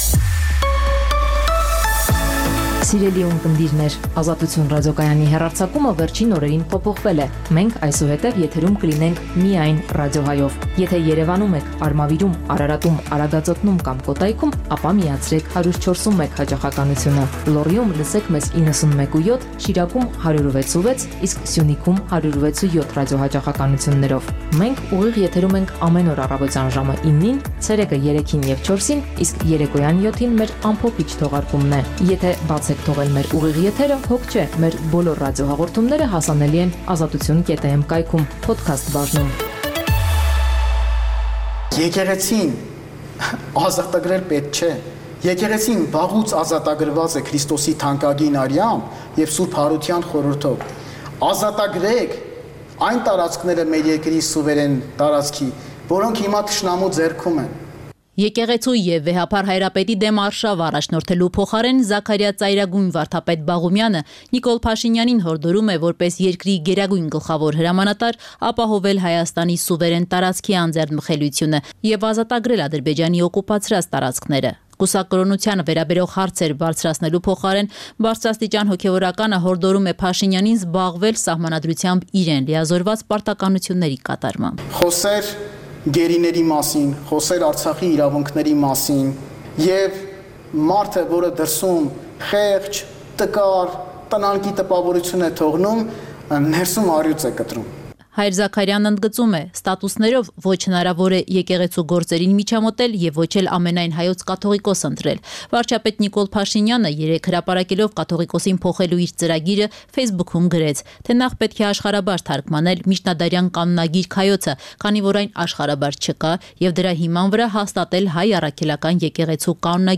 Listen Live - Ուղիղ հեռարձակում - Ազատություն ռ/կ